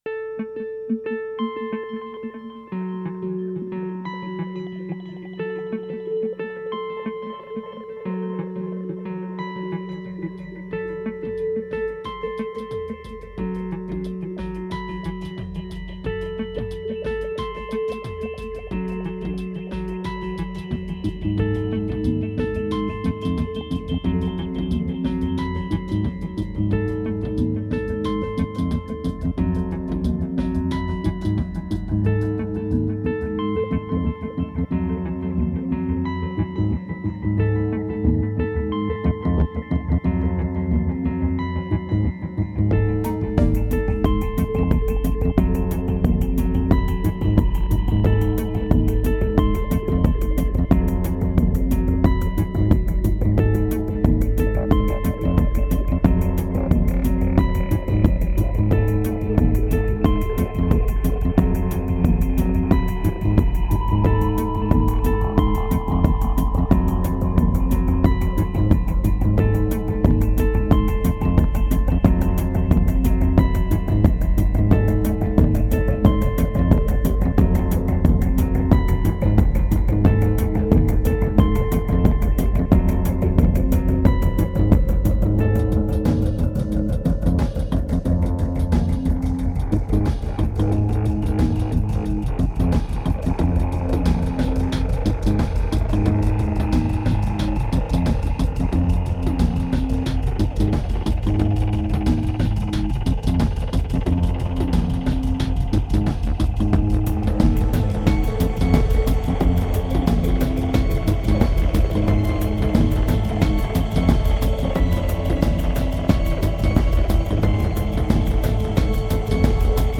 Pure remote desire, strong medication and raw electronica.